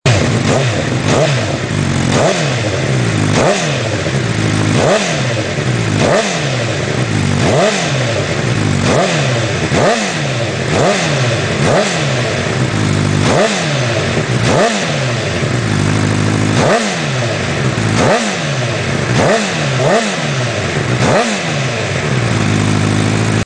Exhaust System HONDA FIREBLADE CBR1000RR : BODIS EXHAUST
Fireblade 08-13 mit db-Killer(2).mp3